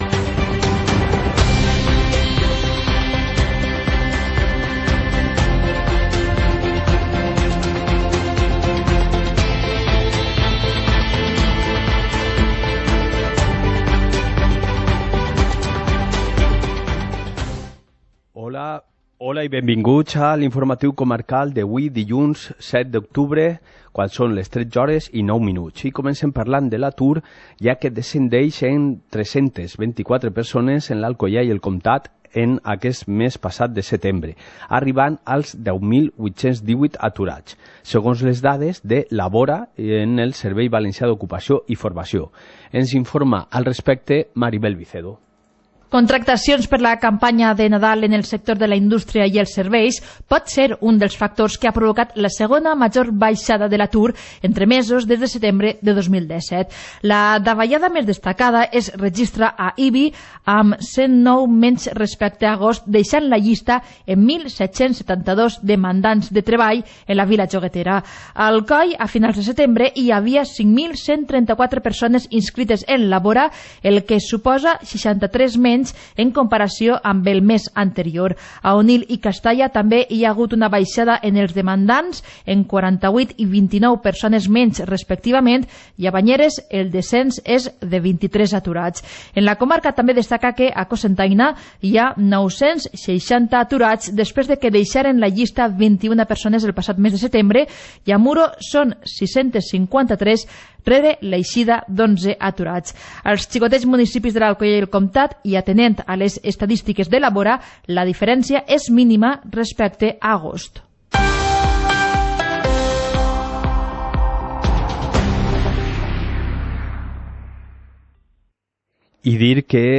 Informativo comarcal - lunes, 07 de octubre de 2019